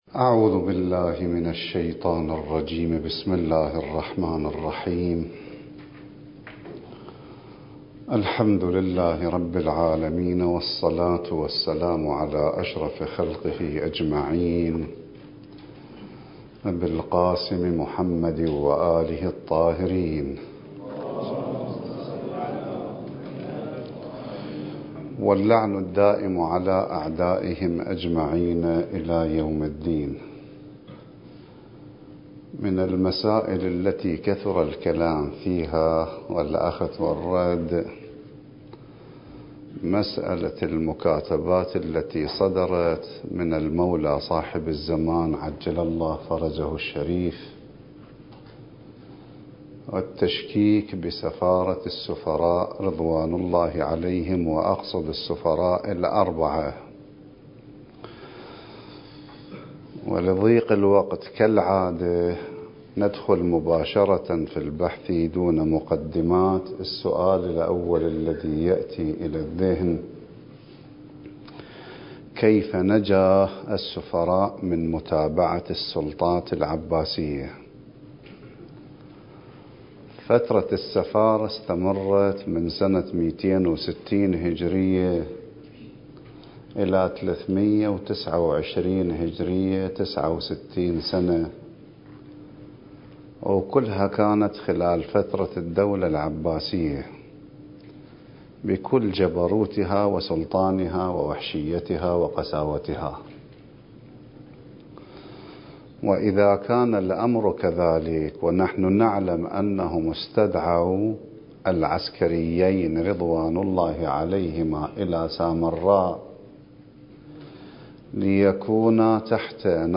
الدورة المهدوية الأولى المكثفة (المحاضرة السابعة عشر)
المكان: النجف الأشرف